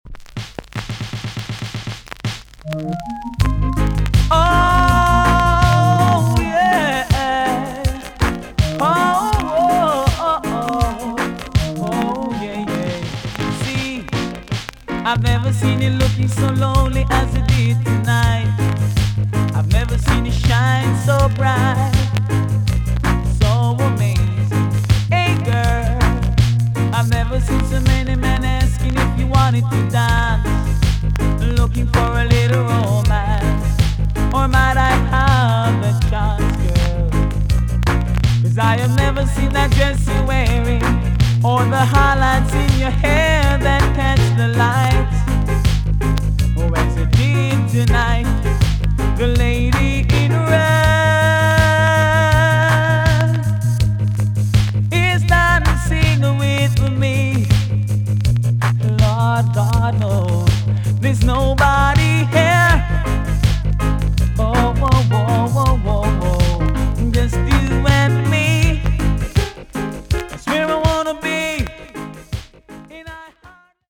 TOP >80'S 90'S DANCEHALL
VG+~VG ok 少し軽いチリノイズが入ります。